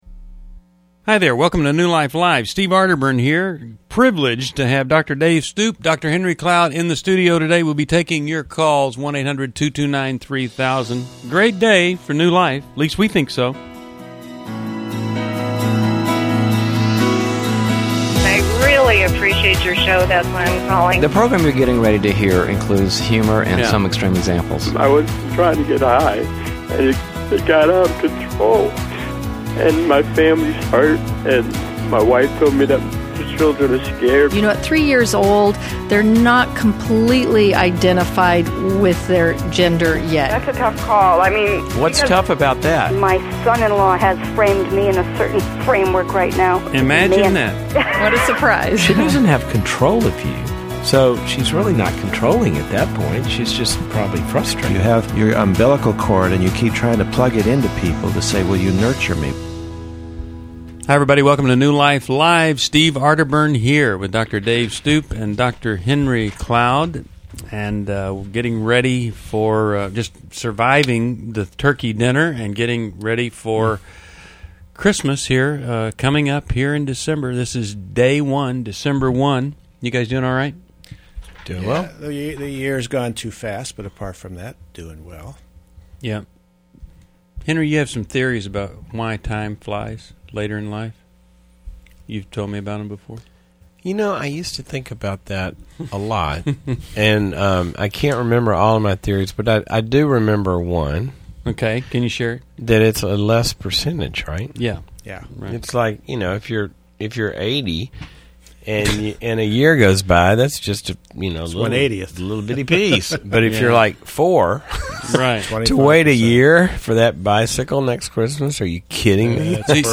Explore family dynamics and personal struggles in New Life Live: December 1, 2011, as callers discuss addiction, affairs, and parenting challenges.